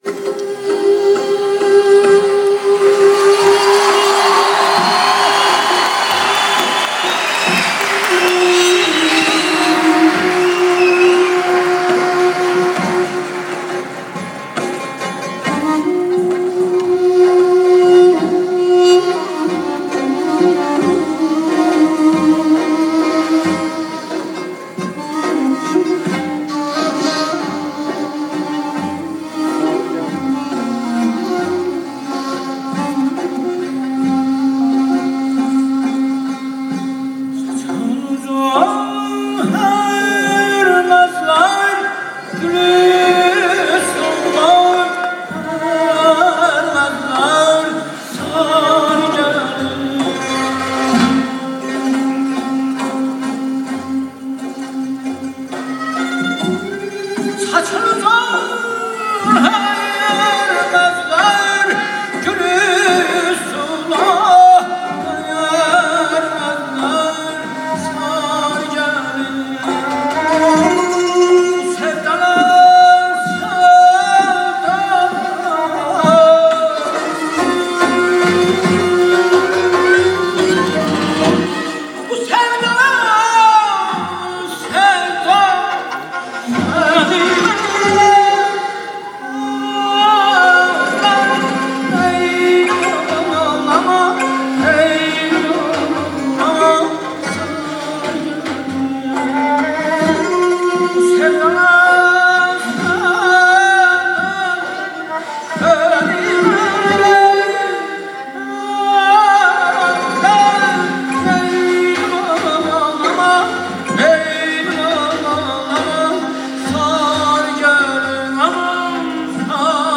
موسیقی فولکلور ایرانی
کنسرت تهران